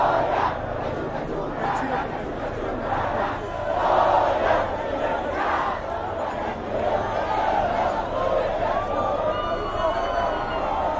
描述：1968年，为了纪念被杀害的学生，墨西哥的一群暴徒......街道，人群，学生，人，墨西哥，西班牙语的一切
Tag: 人群 抗议 暴民